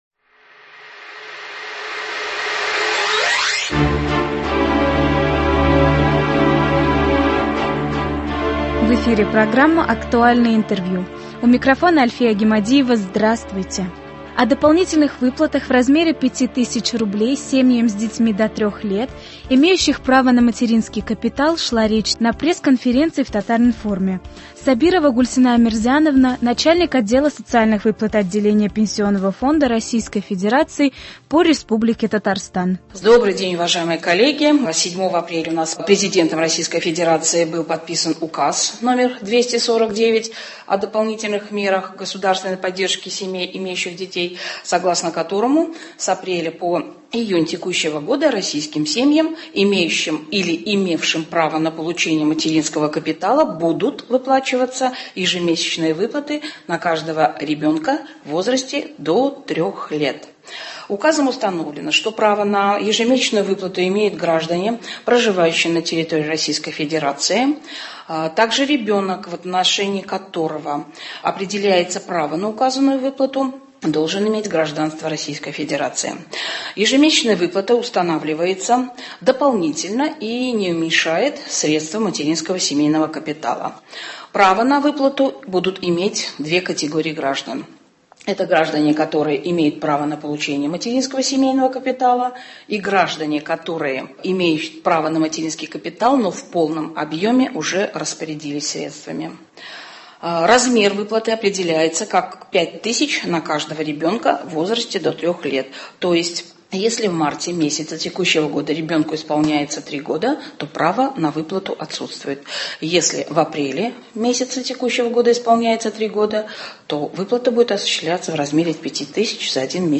«Актуальное интервью». 15 апреля.